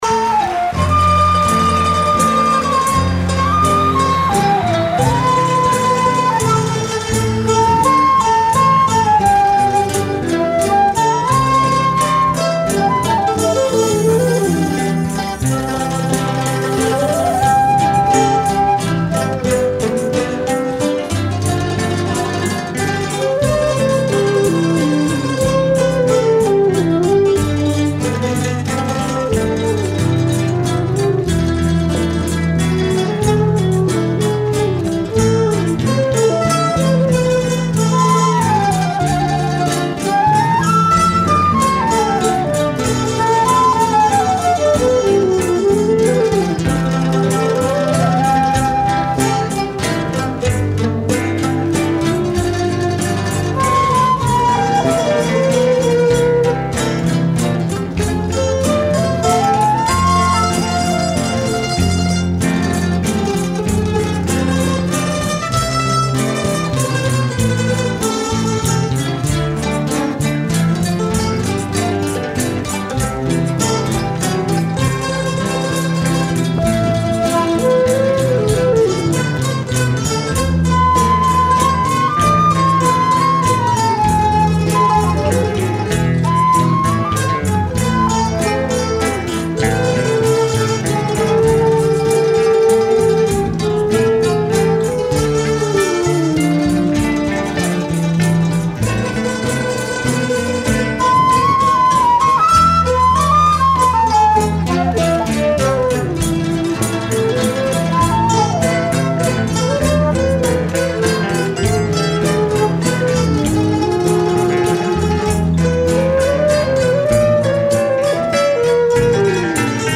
1137   03:50:00   Faixa:     Valsa
Bandolim